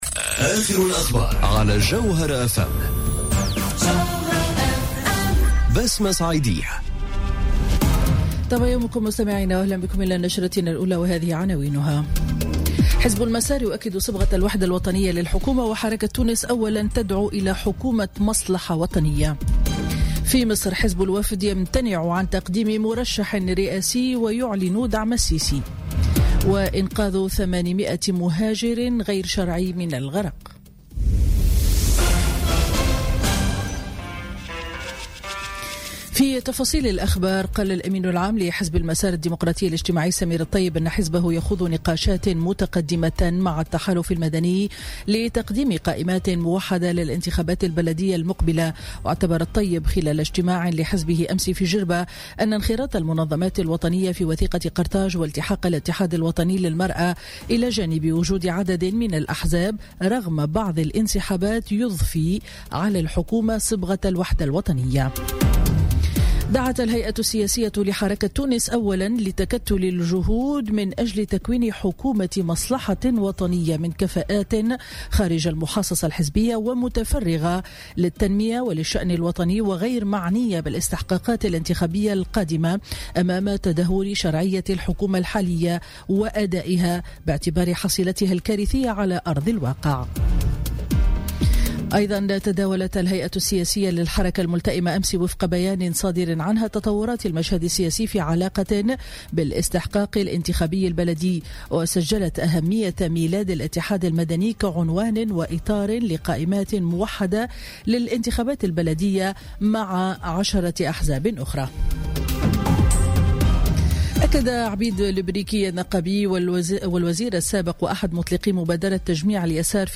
نشرة أخبار منتصف النهار ليوم الأحد 28 جانفي 2018